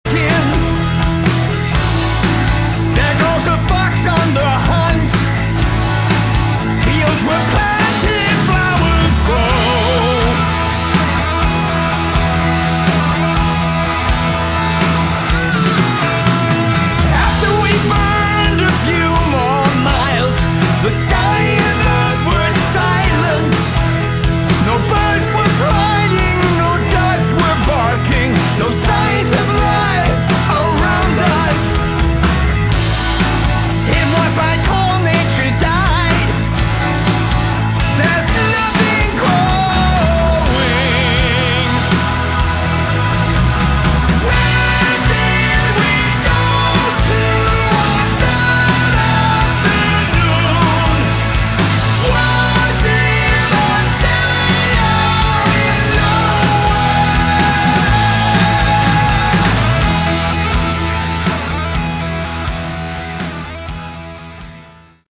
Category: Hard Rock
vocals